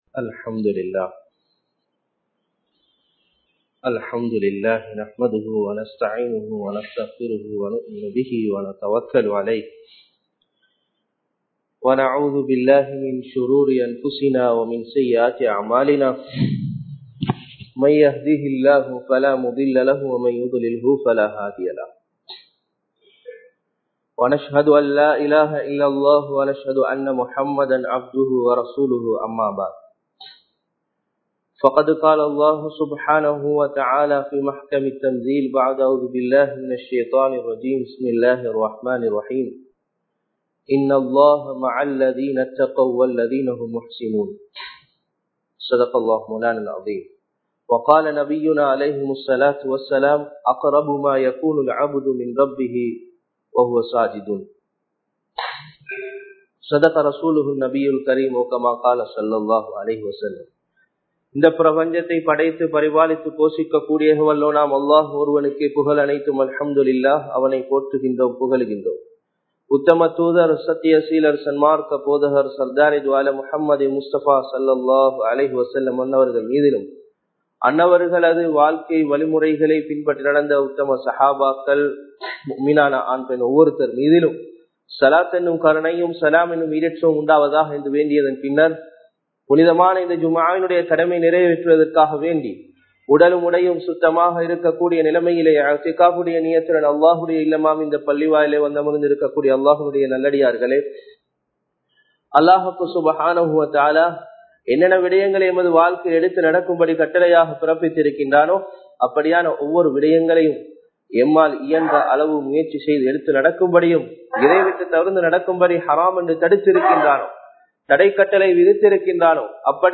வெற்றி பெற ஒரு வழி | Audio Bayans | All Ceylon Muslim Youth Community | Addalaichenai
Saliheen Jumuah Masjith